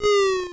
LiftFall.wav